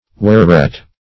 Wherret \Wher"ret\, n.